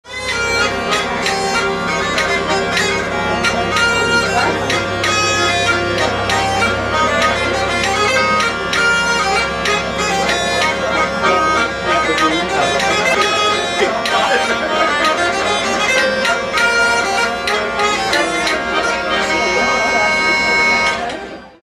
LA FÊTE DE LA VIELLE
Ici un modèle original, recouvert de peau de chèvre (y'a même les cornes !)